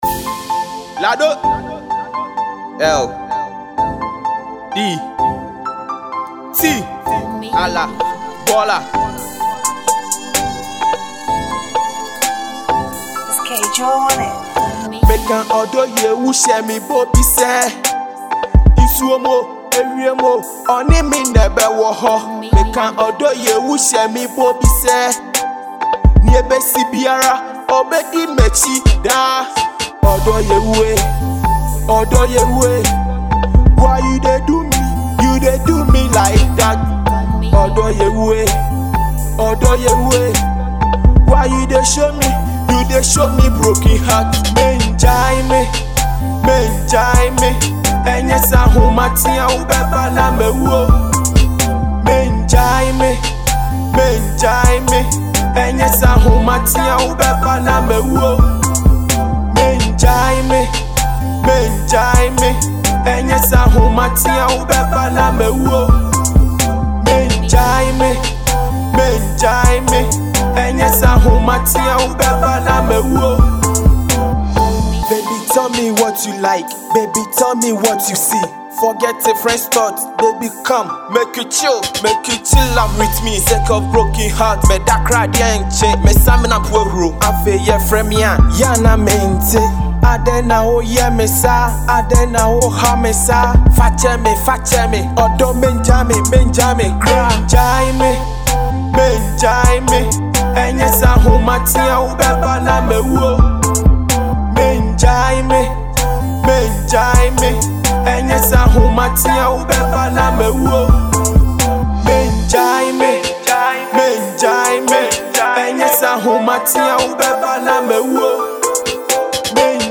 hiphop song